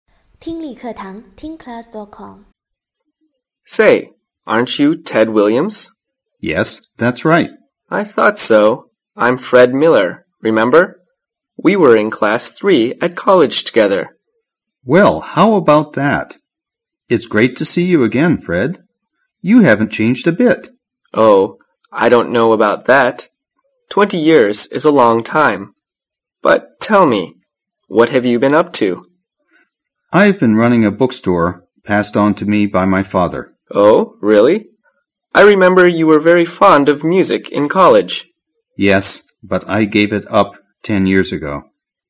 重逢大学校友英语对话-锐意英语口语资料库11-10